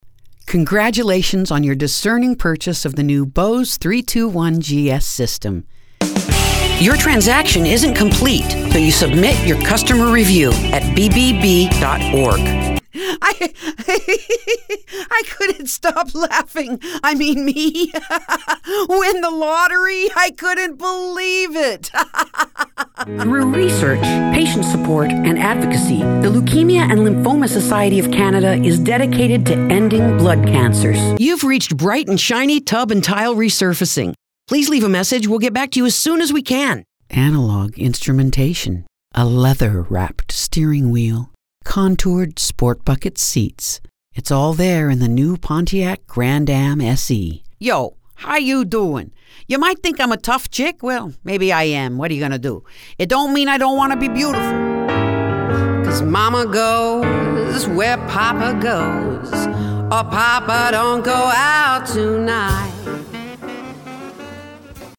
VOICE OVER: Narration, Commercials, Audio Books